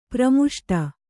♪ pramuṣṭa